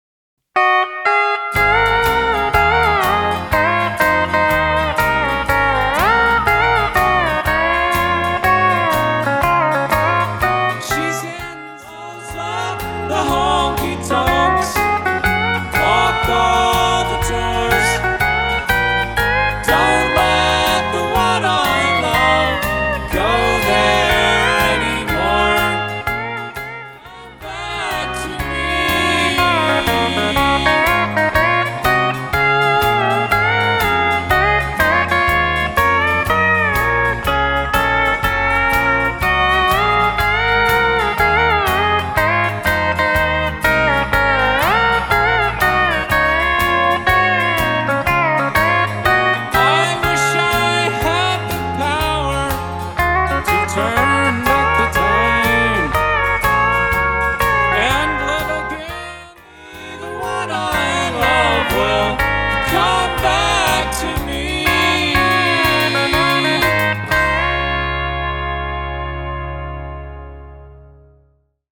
pedal steel guitar
Song Preview: